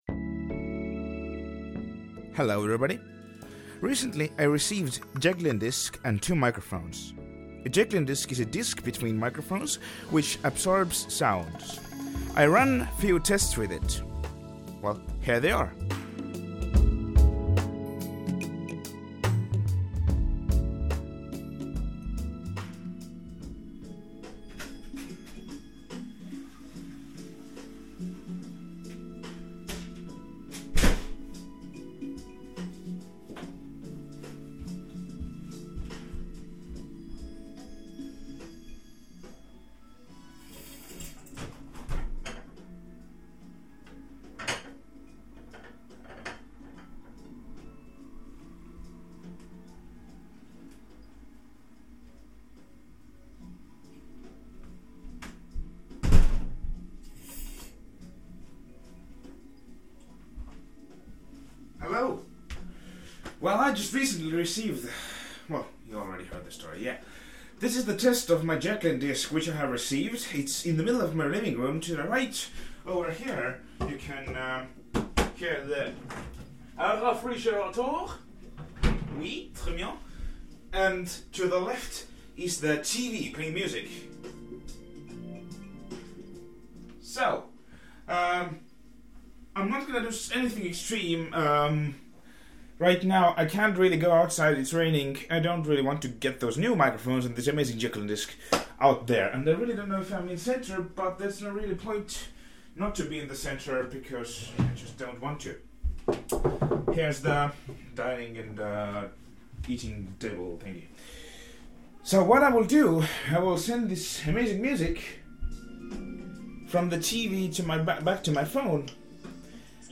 Couple of microphone tests.
I recently received a jecklin disc. Here are some tests with the disc and cad m179 microphones.